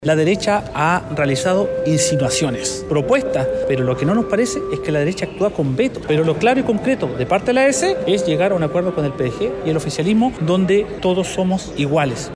Héctor Barría, jefe de bancada DC, dice que lo concreto es que en un acuerdo con el oficialismo y el PDG, ‘todos son iguales’.